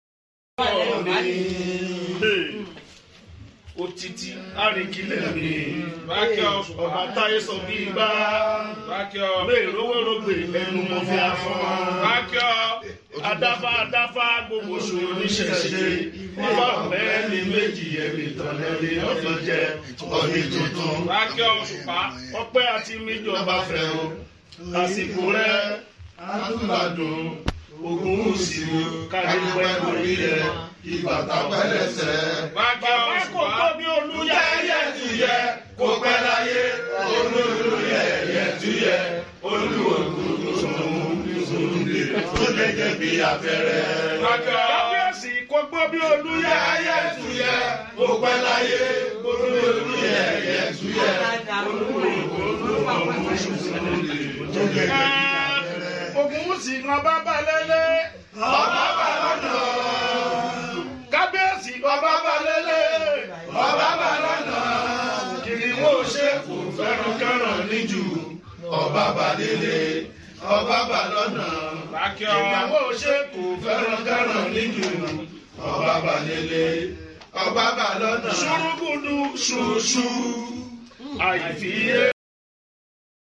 Fuji singer Saheed Osupa: visits sound effects free download